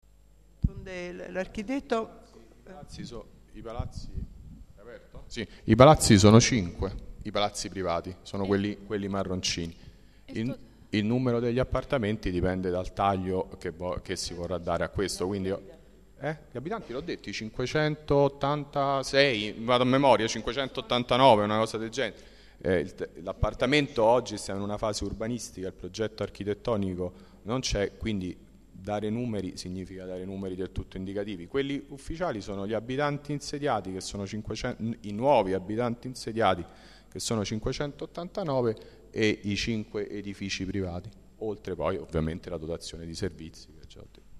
Assemblea
Registrazione integrale dell'incontro svoltosi il 4 aprile 2013 presso la sala consiliare del Municipio Roma VIII